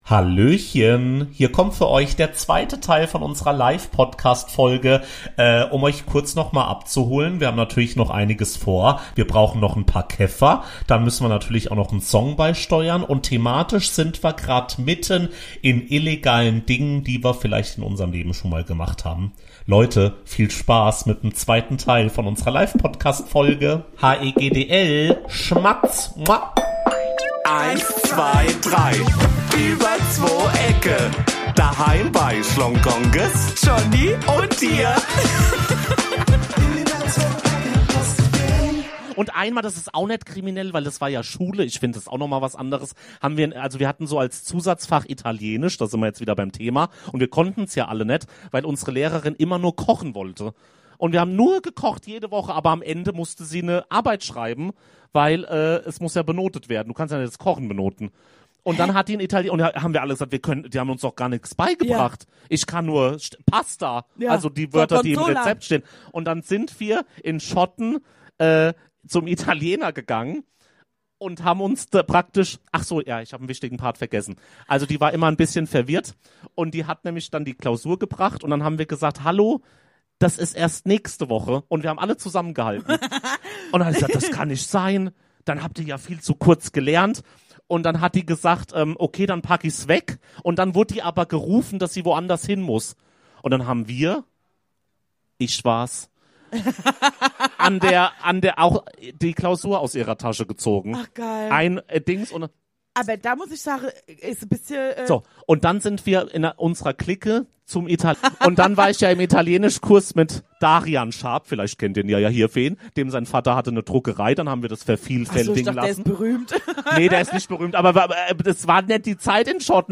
Der zwote Teil unserer Live-Folge ausm Vogelsberg.
Wir beantworten weiter alle Fragen vor Ort und steuern natürlich die Käffer und die Songs der Woche bei! Ey, das war echt so so so so so besonders ne Folge aufzuzeichnen, bei der so viele von euch dabei waren!